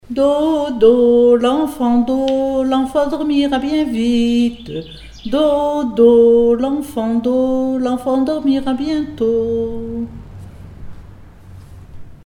berceuse
Comptines et formulettes enfantines
Pièce musicale inédite